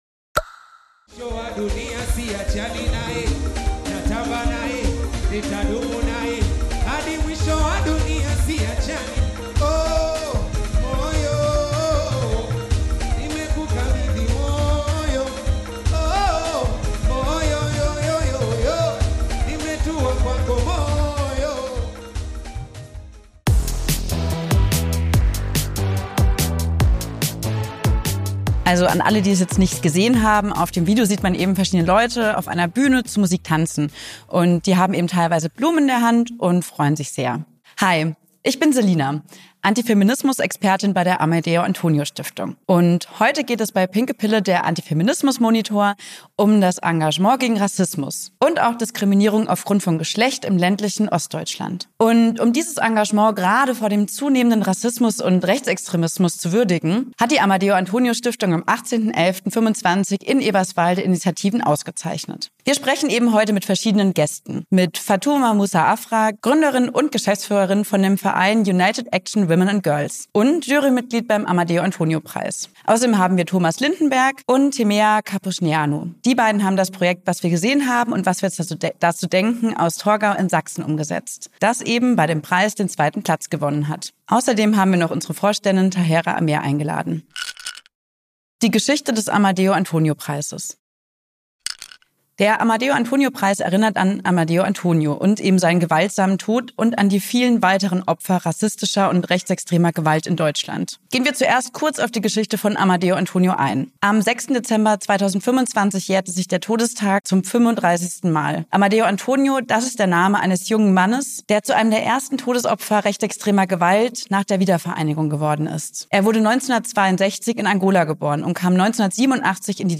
Den Preis nehmen wir zum Anlass, uns in dieser Folge mit Rassismus auseinanderzusetzen – mit besonderem Fokus auf Frauen und Mädchen. Dafür sprechen wir mit verschiedenen Personen im Zusammenhang mit dem Preis. In Pinke Pille – der Antifeminismus-Monitor geht es diesmal um die Geschichte von Amadeu Antonio, einem der ersten Todesopfer rechtsextremer Gewalt im wiedervereinten Deutschland, um die besondere Situation von Frauen und Mädchen in Brandenburg und darum, warum das Empowerment junger Rom*nja in Torgau so zentral ist.